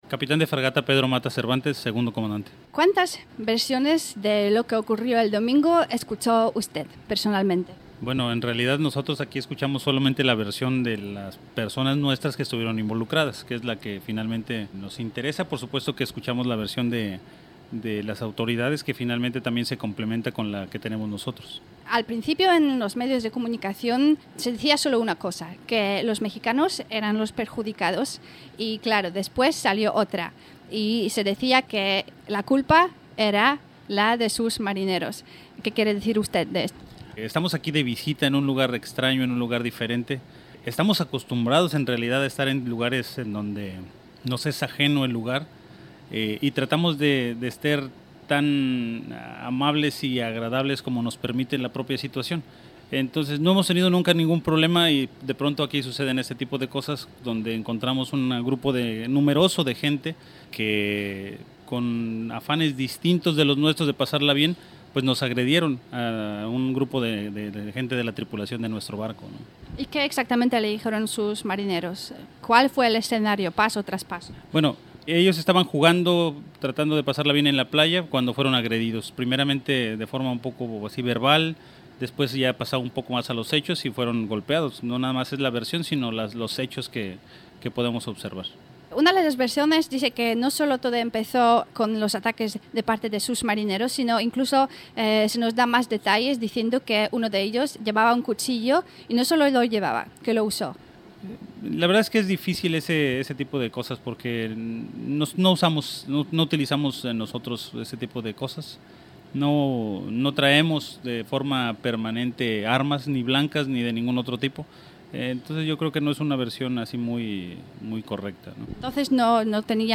Rozmowa w jęz. hiszpańskim